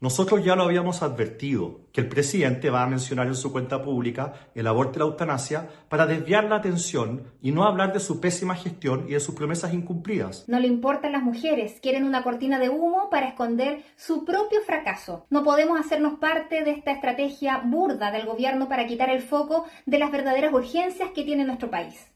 Por ejemplo, el presidente de la UDI, Guillermo Ramírez, señaló que La Moneda utilizará el aborto para desviar la atención.
Asimismo, la senadora María José Gatica (RN) planteó que se trata de “una cortina de humo”.